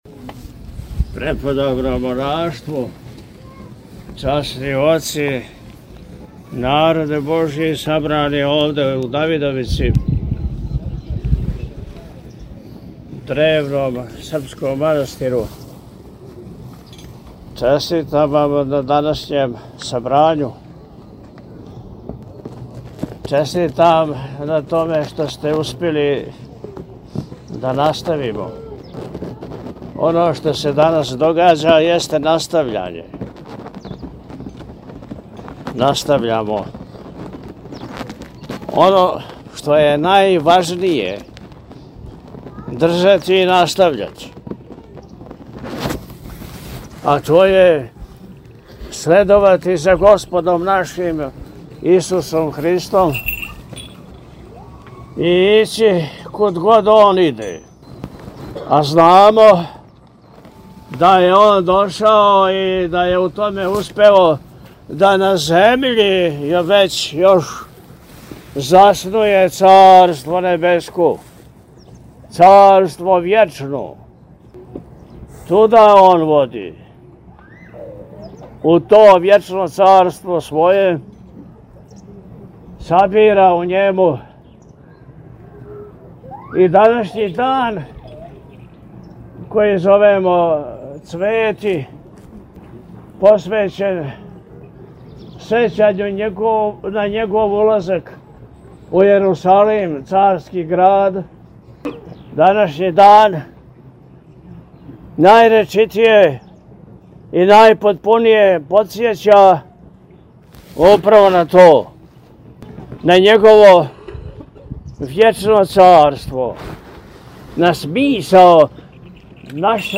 Високопреосвећени Митрополит Атанасије сабранима се обратио пригодном пастирском беседом у којој је, између осталог, рекао: – Настављамо оно што је најважније држати и настављати, а то је следовати за Господом нашим Исусом Христом, и ићи куд год Он иде.